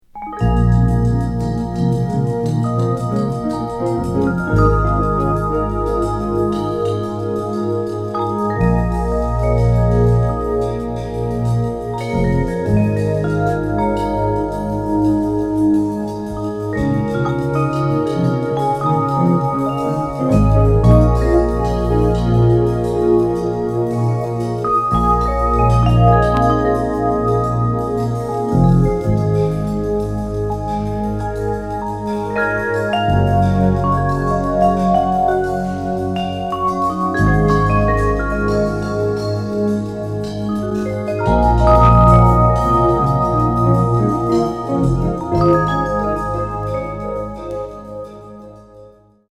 キーワード：ミニマル